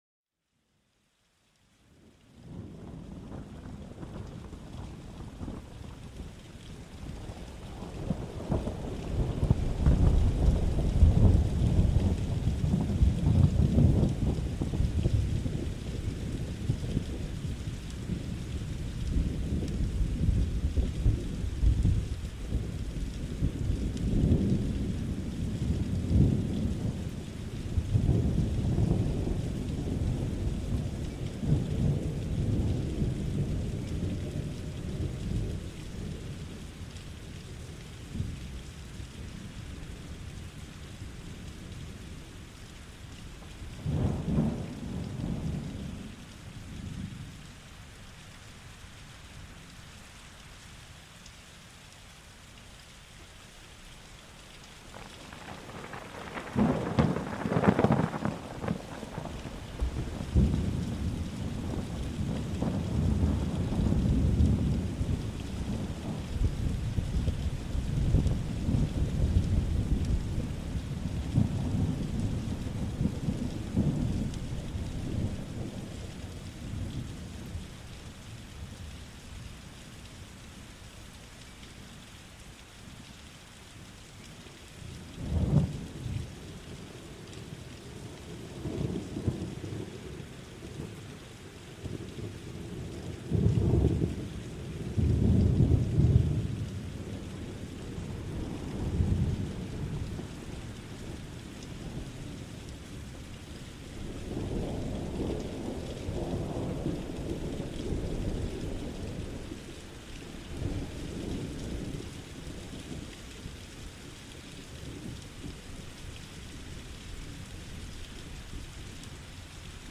SONIDOS DE LA NATURALEZA PARA LA RELAJACIÓN